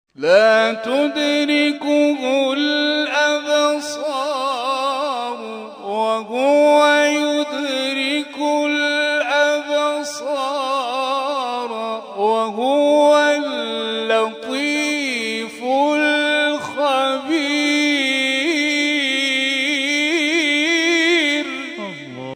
فرازهایی از محفل این هفته آستان عبدالعظیم(ع)
گروه جلسات و محافل: محفل انس با قرآن این هفته آستان عبدالعظیم الحسنی(ع) با تلاوت قاریان ممتاز و بین‌المللی کشورمان برگزار شد.